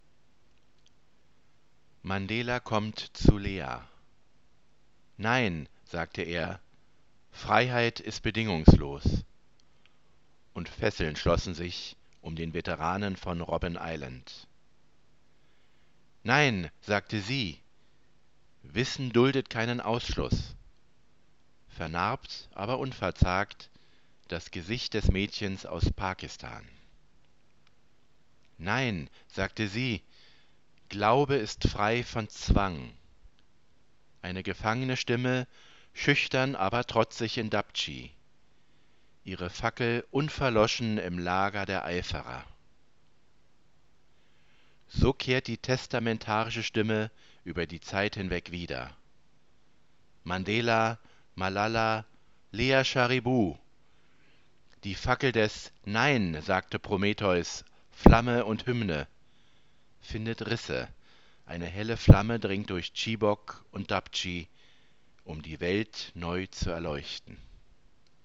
A reading of the poem in German